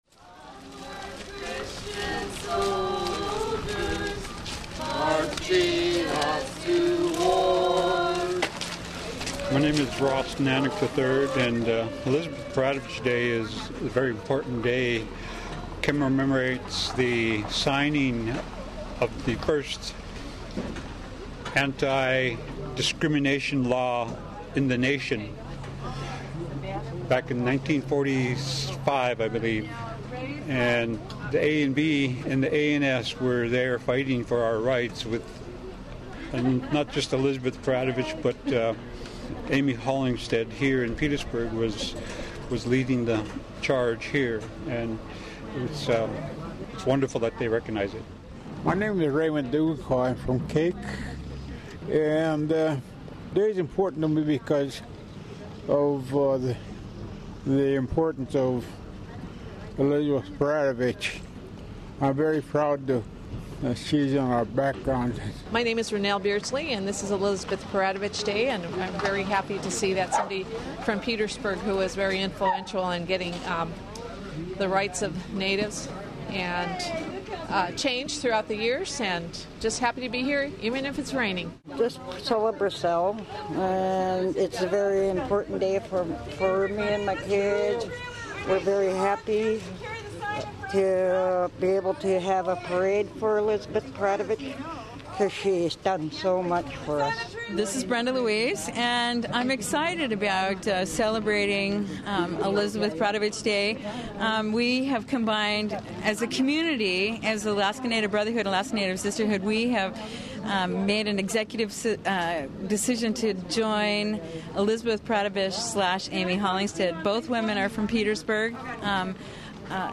Alaska Native Brotherhood and Sisterhood members and others braved the rain and wind Saturday afternoon to celebrate Elizabeth Peratrovich Day with a small parade down Main Street in Petersburg.
As they marched down Main Street, the participants got applause from parade-watchers as they sang “Onward Christian Soldiers,” which is the anthem for the ANB and ANS.